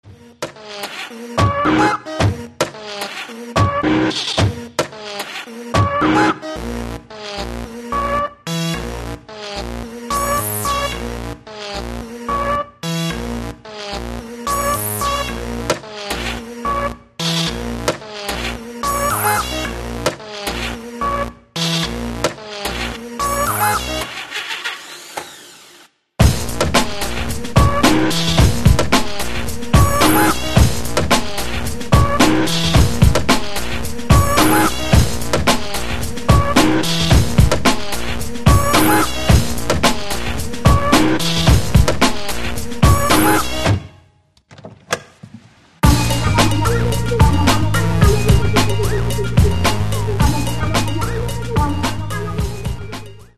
Каталог -> Рок та альтернатива -> Електронна альтернатива